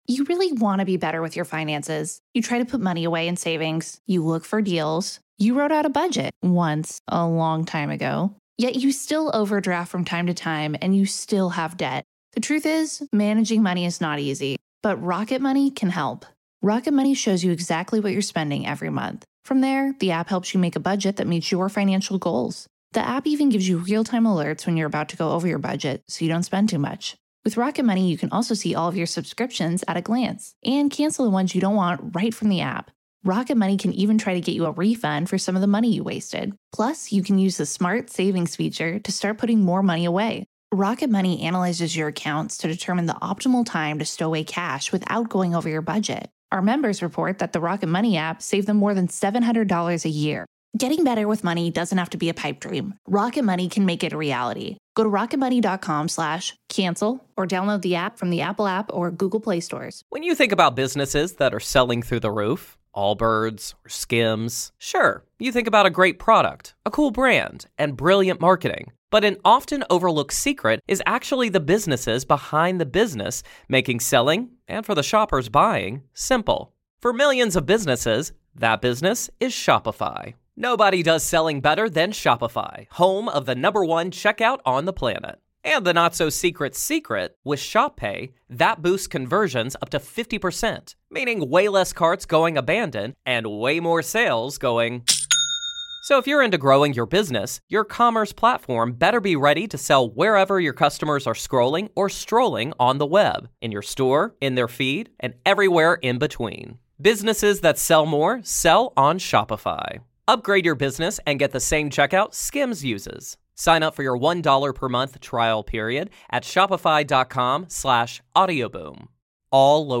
Tennis Feuds, Fan Questions & Interview